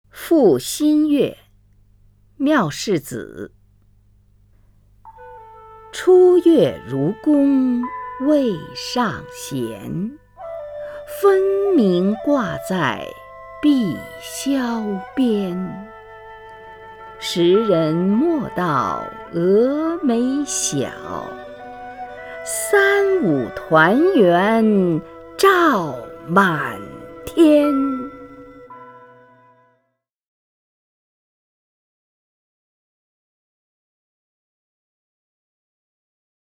虹云朗诵：《赋新月》(（唐）缪氏子) （唐）缪氏子 名家朗诵欣赏虹云 语文PLUS
（唐）缪氏子 文选 （唐）缪氏子： 虹云朗诵：《赋新月》(（唐）缪氏子) / 名家朗诵欣赏 虹云